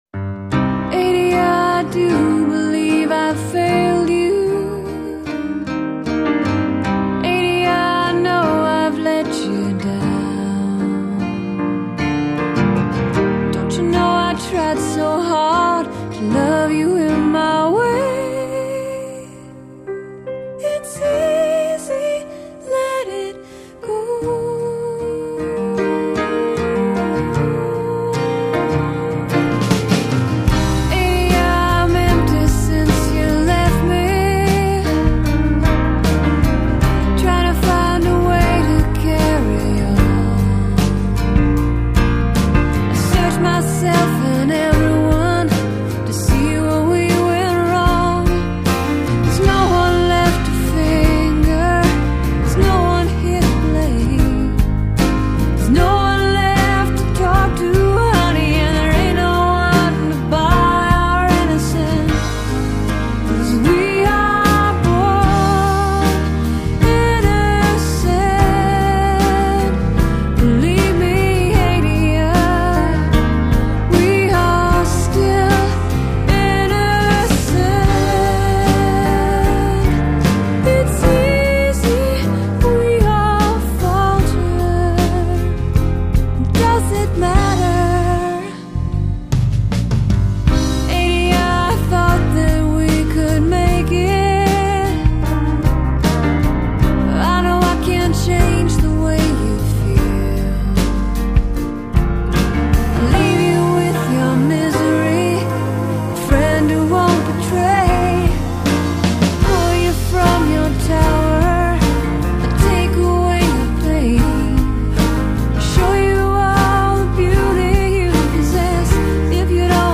天籁美声
她的唱腔中带着标志性的鼻音，轻柔地，舒缓地衬托出一种难以言传的味道